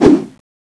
slash1.wav